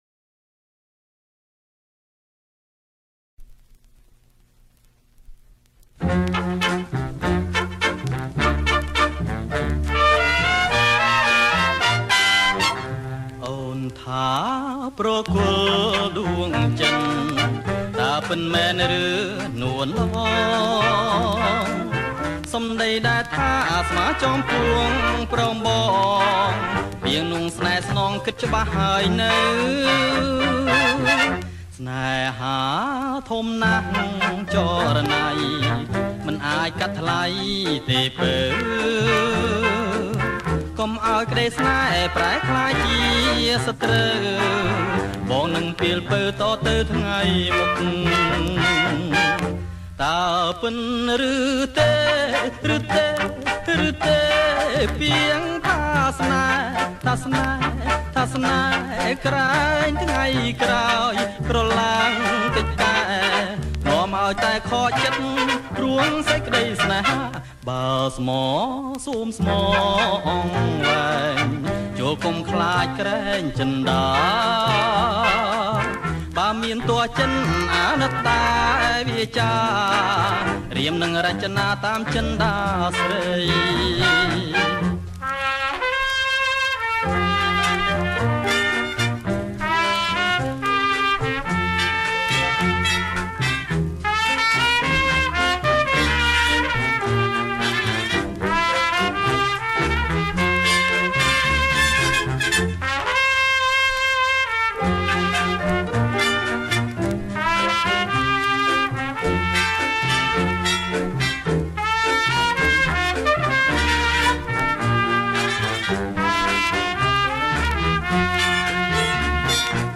• ប្រគំជាចង្វាក់ Cha Cha Cha Surf